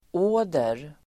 Uttal: ['å:der]